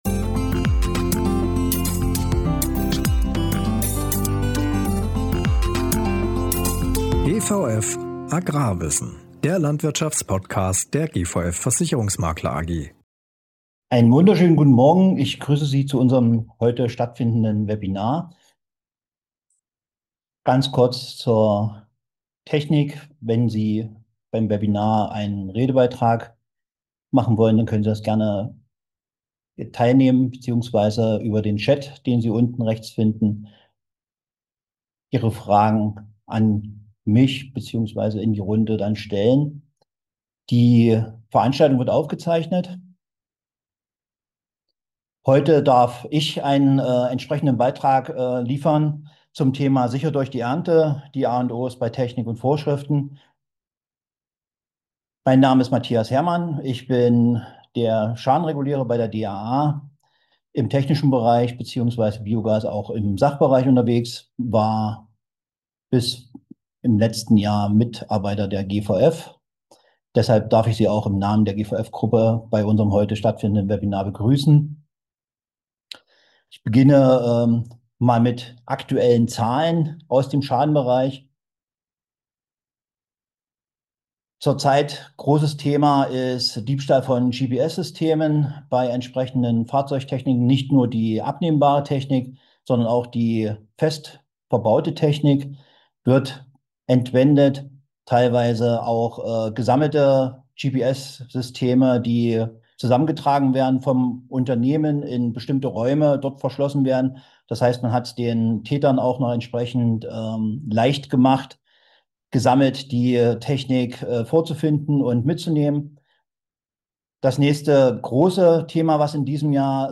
In unserem Webinar zeigen wir Ihnen, wie Sie mit der richtigen Planung und dem nötigen Wissen Schäden an Maschinen und Erntegut vermeiden sowie einen reibungslosen Ablauf sicherstellen.